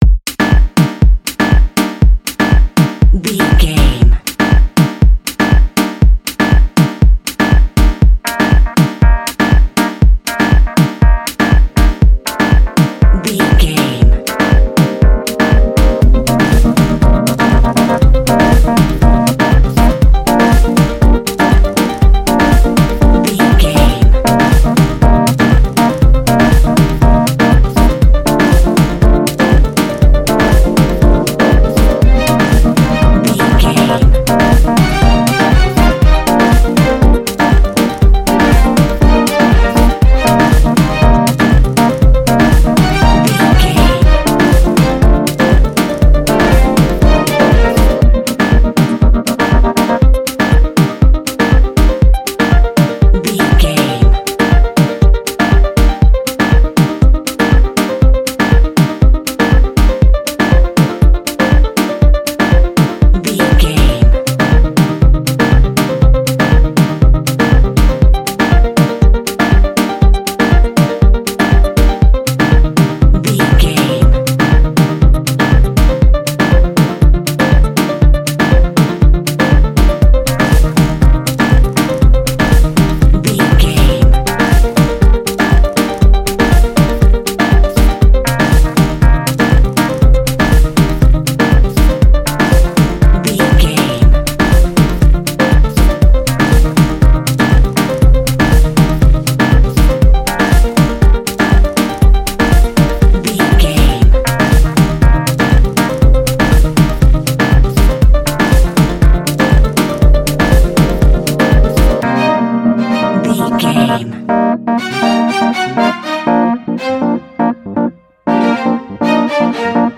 Club Dance Music.
Aeolian/Minor
groovy
smooth
futuristic
uplifting
drum machine
synthesiser
strings
house
electro house
synth bass